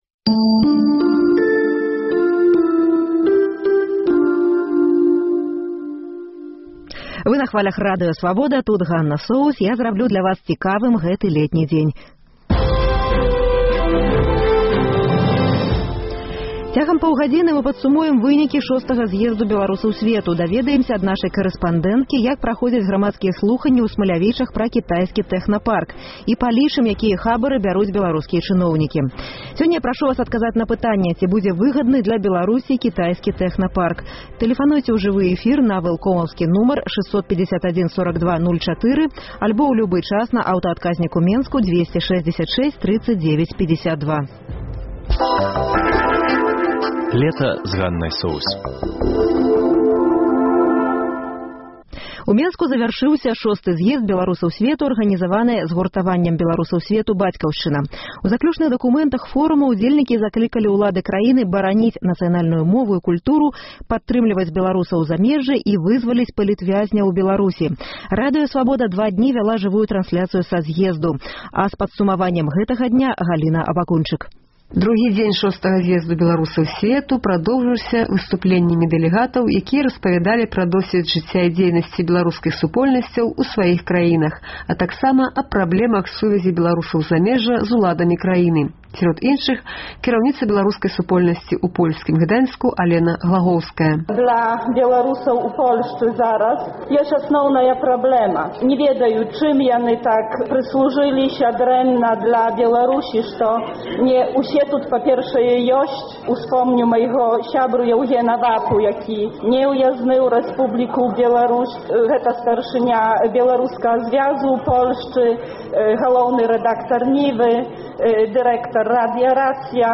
Вас чакаюць жывыя ўключэньні карэспандэнтаў «Свабоды» з шостага зьезду беларусаў сьвету і грамадзкіх слуханьняў пра кітайскі тэхнапарк са Смалявічаў То Чарнобыль, то сьвіная чума — рэпартаж з гомельскага рынку.